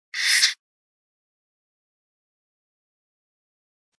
descargar sonido mp3 zas corte